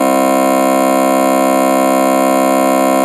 Play Weird Ear Sound - SoundBoardGuy
Play, download and share Weird ear sound original sound button!!!!